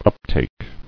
[up·take]